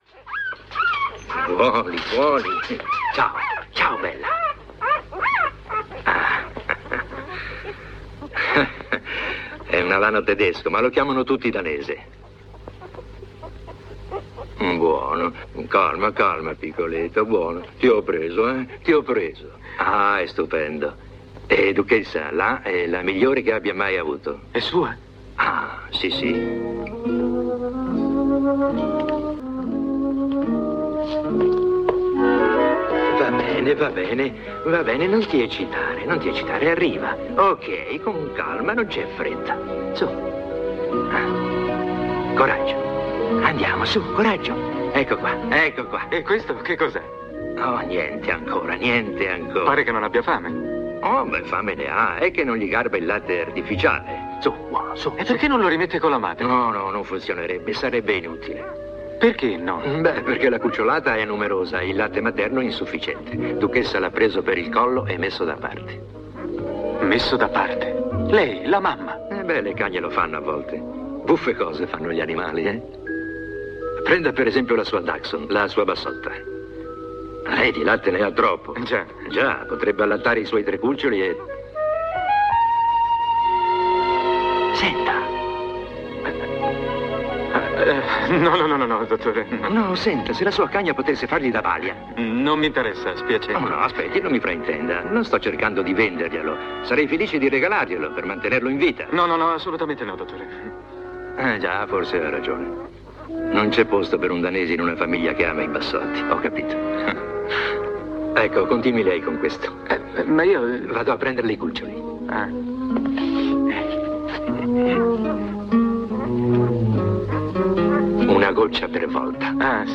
voce di Gino Baghetti nel film "Quattro bassotti per un danese", in cui doppia Charlie Ruggles.